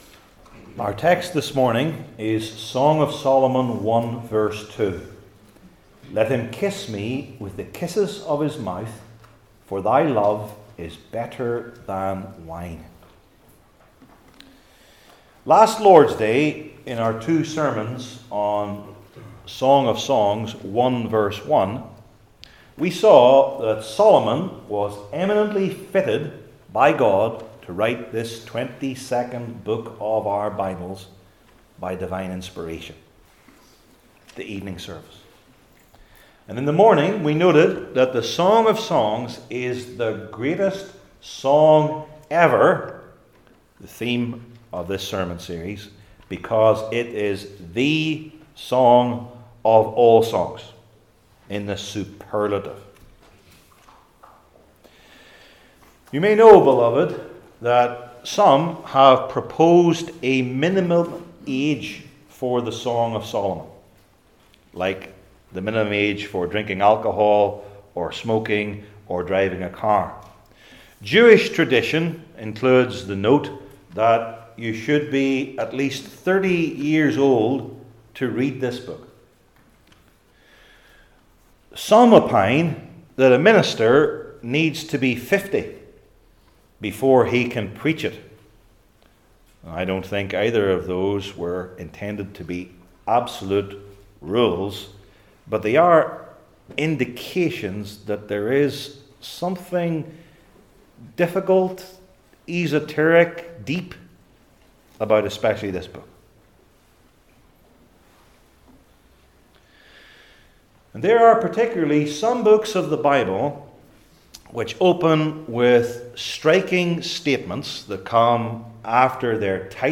Old Testament Sermon Series I. The Believer’s Desire II.